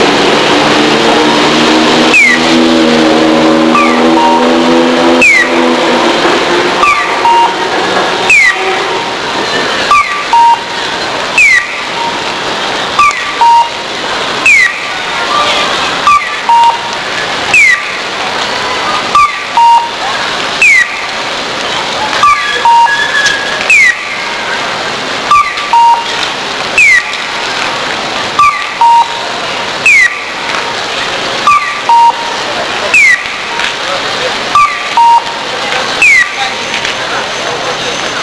各地で音響信号を録って参りましたので5ファイルまで大丈夫なようなので5ファイル投稿させていただきます。
また、未編集なために音が悪かったりしますがそちらもご了承ください。
まずは、愛媛県松山市で見つけたスクランブル交差点です。
なんとピヨとカッコーが同時に鳴っています。
(ACbd)→(BDac)→(ACbd)→(BDac)→…という感じにテンポ良く鳴ります。